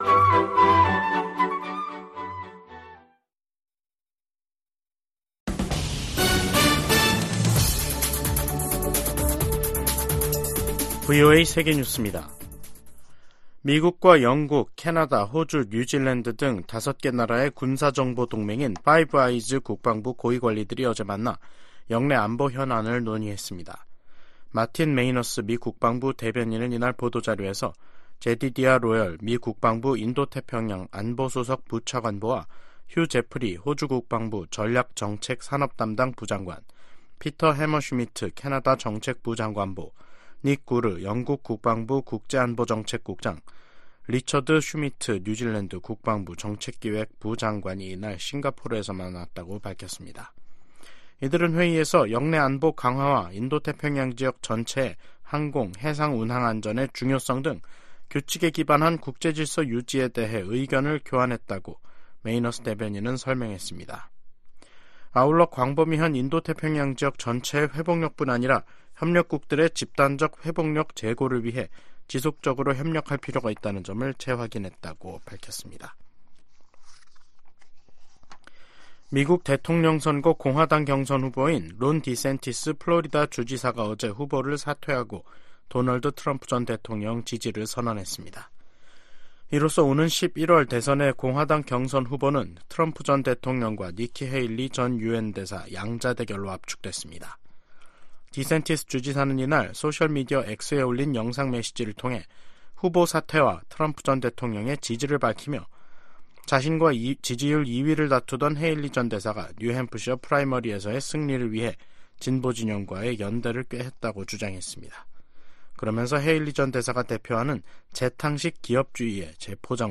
VOA 한국어 간판 뉴스 프로그램 '뉴스 투데이', 2024년 1월 22일 2부 방송입니다. 미 국무부가 북한의 수중 핵무기 시험 주장에 도발을 중단하고 대화에 나서라고 촉구했습니다. 백악관은 수중 핵무기 시험 주장과 관련해 북한이 첨단 군사 능력을 계속 추구하고 있음을 보여준다고 지적했습니다. 과거 미국의 대북 협상을 주도했던 인사들이 잇달아 김정은 북한 국무위원장의 최근 전쟁 언급이 빈말이 아니라고 진단하면서 파장을 일으키고 있습니다.